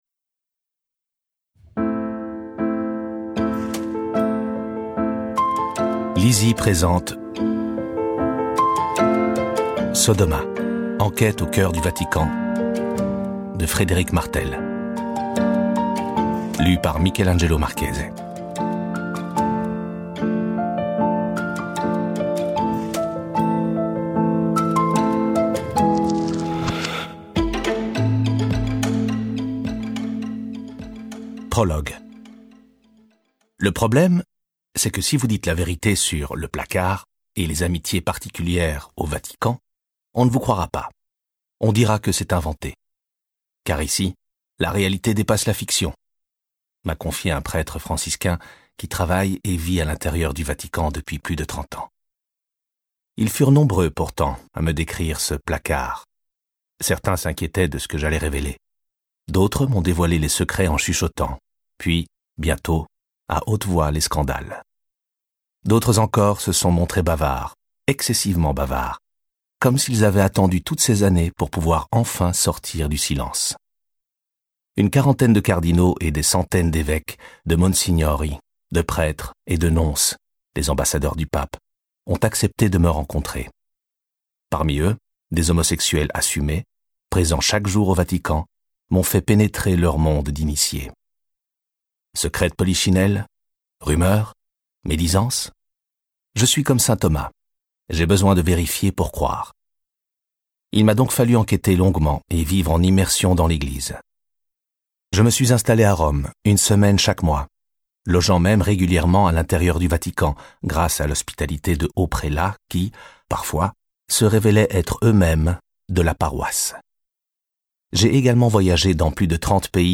Extrait gratuit - Sodoma de Frédéric Martel